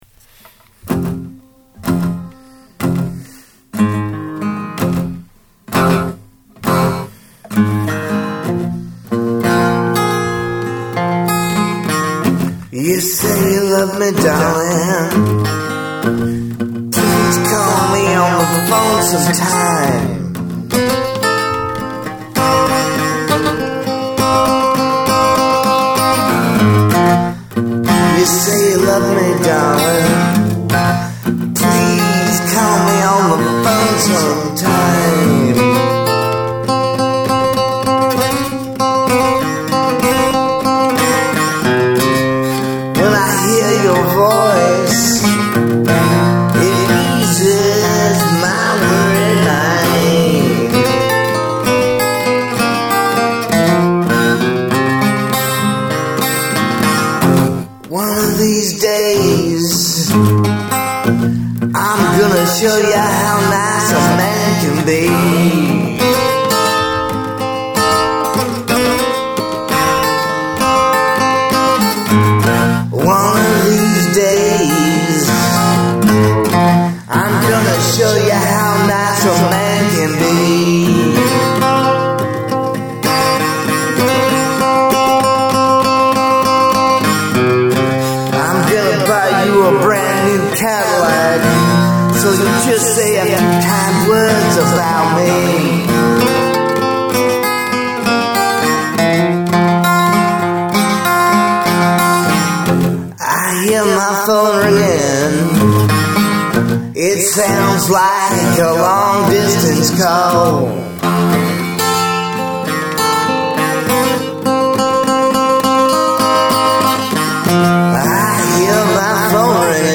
Hope you enjoy another live recording! 🙂